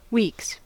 Ääntäminen
Ääntäminen US : IPA : [wiːks] Haettu sana löytyi näillä lähdekielillä: englanti Käännöksiä ei löytynyt valitulle kohdekielelle. Weeks on sanan week monikko.